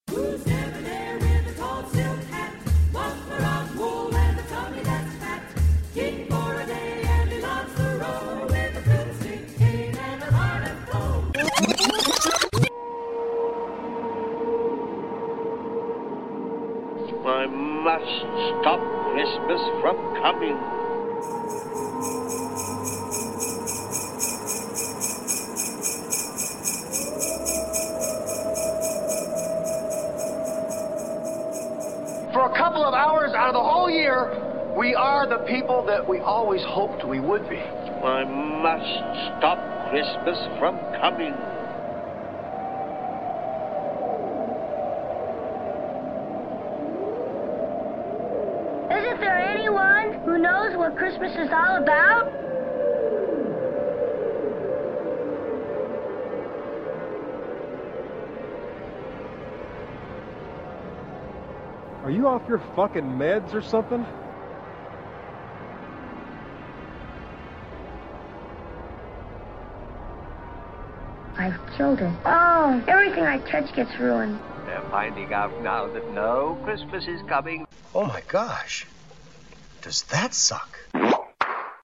Tags: silly nutty weird noise sound collage